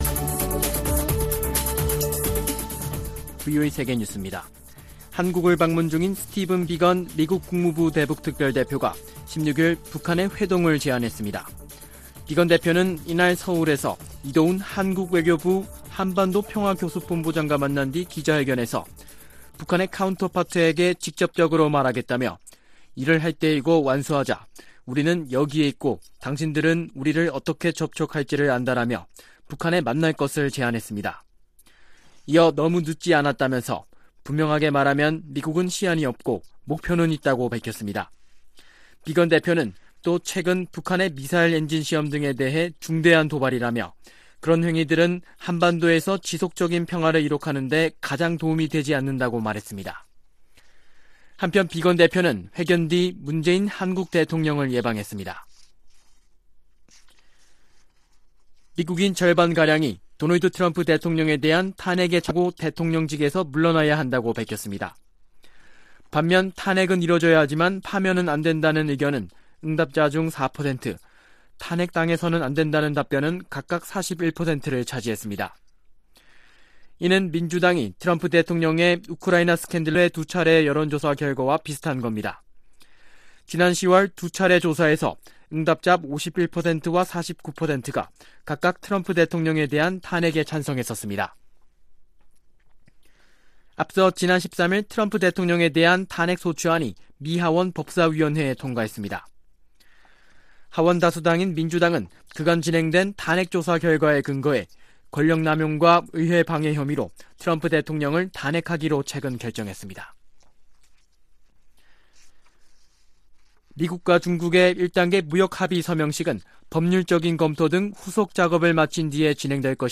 VOA 한국어 아침 뉴스 프로그램 '워싱턴 뉴스 광장' 2018년 12월 17일 방송입니다. 서울을 방문한 스티븐 비건 미 국무부 대북특별대표가 북한 측에 만남을 공개 제안했습니다. 마크 에스퍼 미 국방장관은 북한 문제에서 진전을 낼 수 있는 길은 외교적 정치적 합의뿐이라고 밝혔습니다.